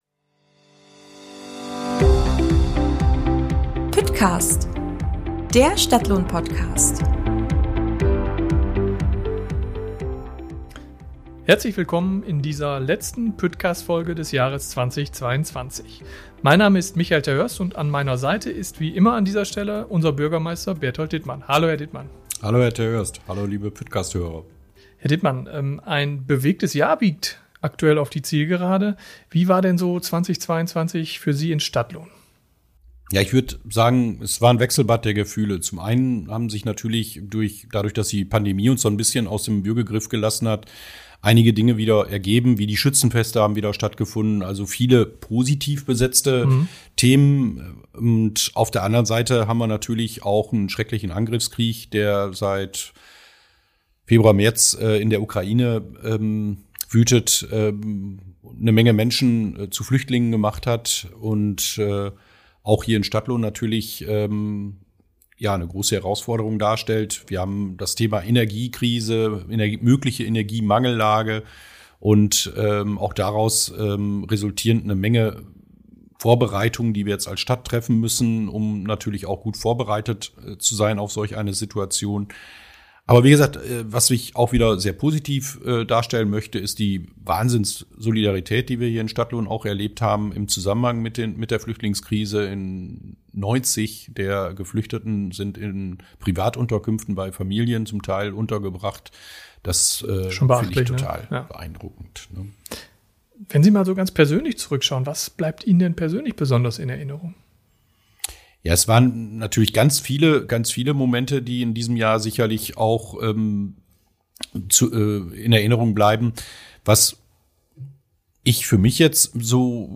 Der zweite Teil des Interviews steht dann ganz im Zeichen des Klimaschutzes.